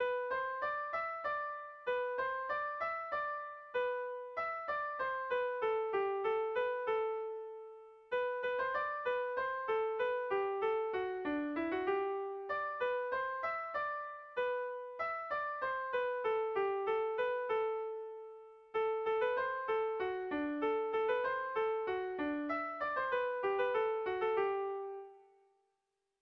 Bertso melodies - View details   To know more about this section
AABD